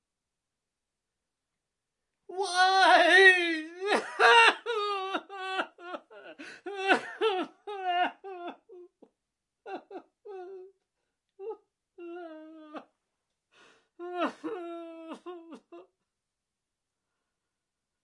09.啜泣
描述：声音，效果
标签： 效应抽泣 声音
声道立体声